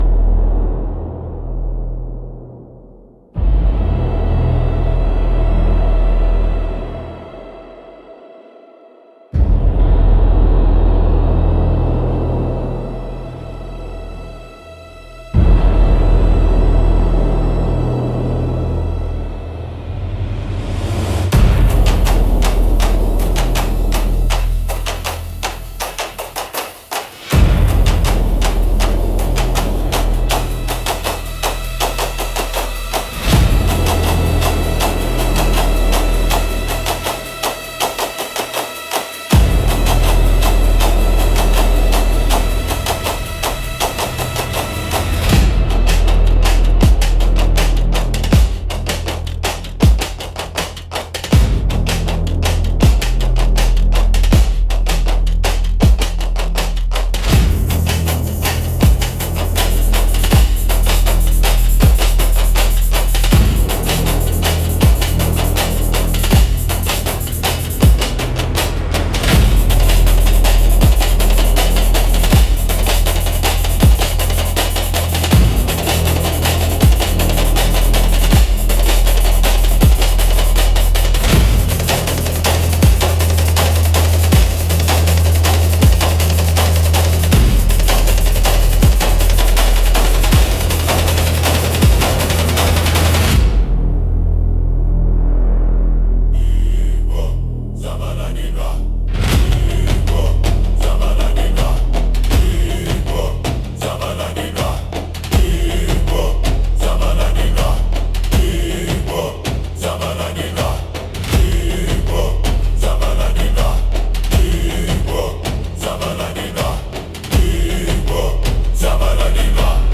Instrumental - Dawn over the Ruins